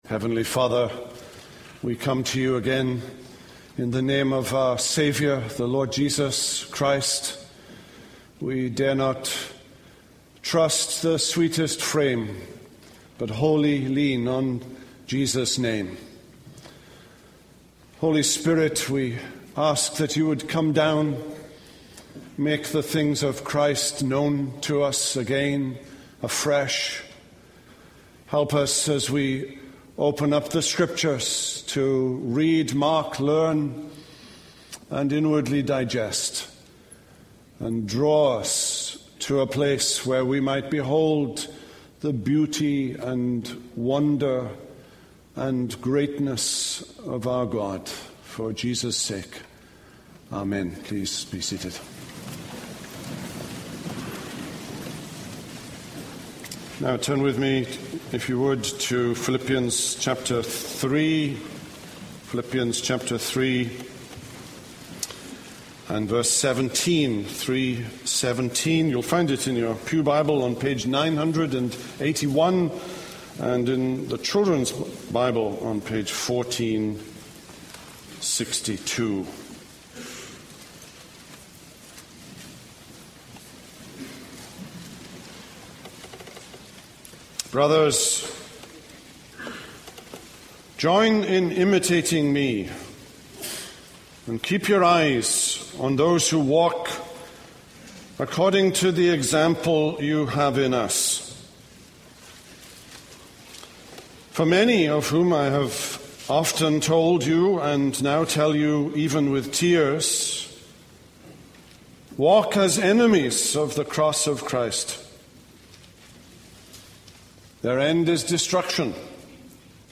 This is a sermon on Philippians 3:17-4:1.